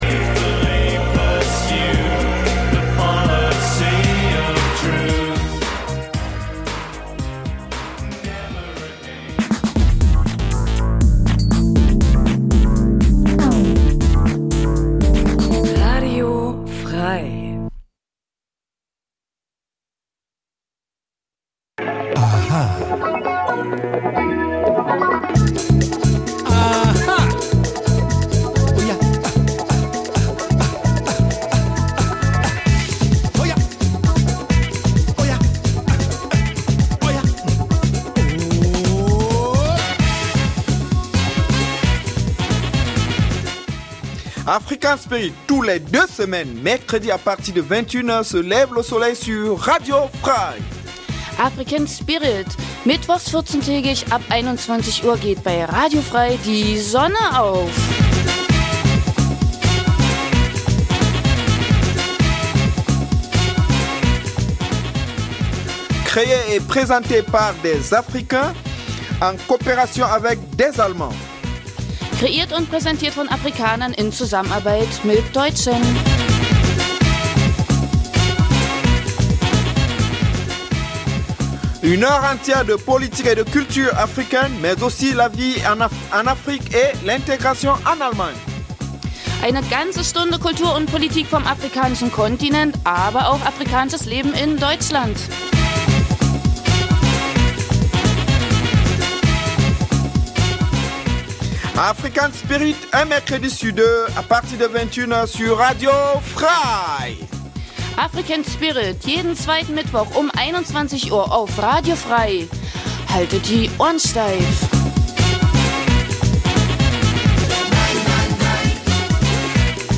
Neben aktuellen Nachrichten gibt es regelmäßig Studiogäste, Menschen die in irgendeiner Form etwas mit Afrika zu tun haben: Zum Beispiel in Thüringen lebende Afrikaner, die uns über ihr Heimatland berichtet aber auch davon welche Beziehungen sie zu Deutschland haben oder Deutsche die aus beruflichen Gründen in Afrika waren und uns über ihre Erfahrungen berichten. Die Gespräche werden mit afrikanischer Musik begleitet.